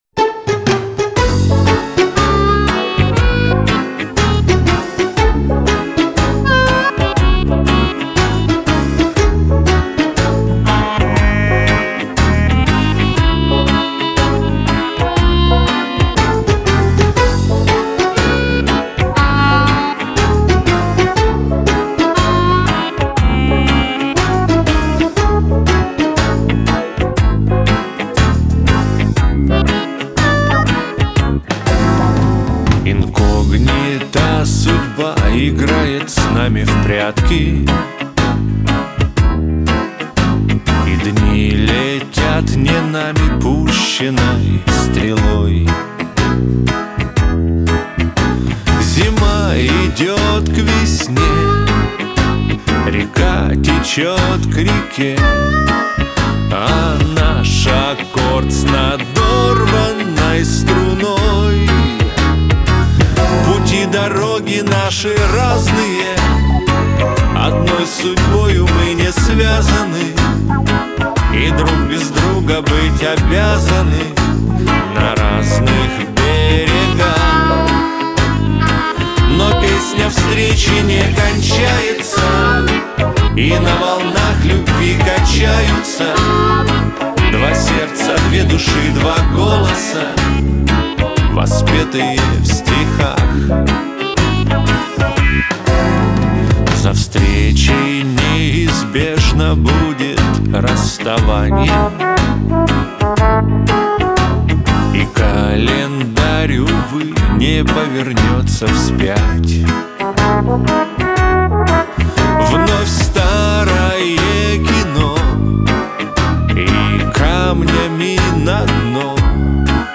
Шансон